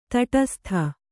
♪ taṭastha